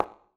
surface_felt2.mp3